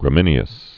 (grə-mĭnē-əs)